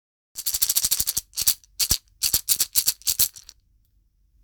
シェケレ(セゲビーズ)極小
ひょうたんにガラスビーズ(セゲビーズ)をつけたかわいいシェケレ。
素材： ひょうたん ガラス 木綿糸